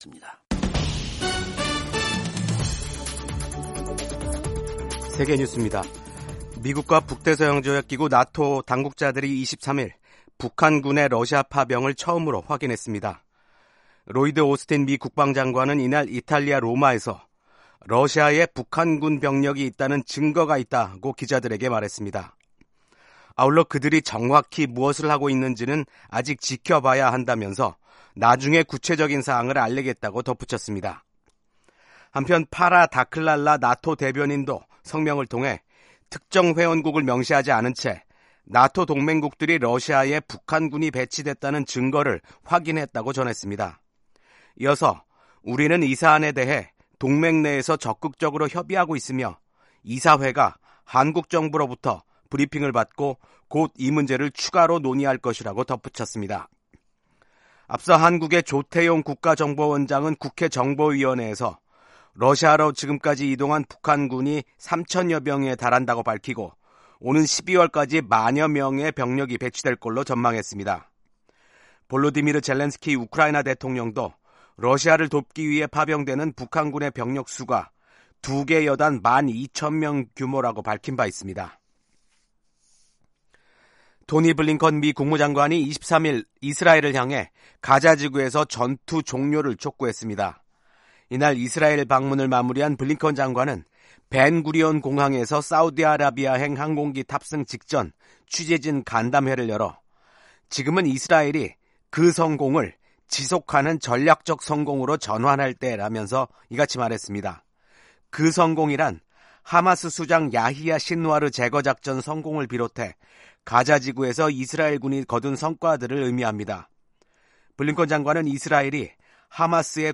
세계 뉴스와 함께 미국의 모든 것을 소개하는 '생방송 여기는 워싱턴입니다', 2024년 10월 24일 아침 방송입니다. 팔레스타인 가자지구 분쟁을 이제 끝내야 할 때라고 토니 블링컨 미국 국무장관이 강조했습니다. 미국 대선 선거 운동이 막바지 단계에 들어선 가운데 공화당 후보인 도널드 트럼프 전 대통령과 민주당 후보 카멀라 해리스 부통령이 서로를 겨냥한 공방을 이어갔습니다.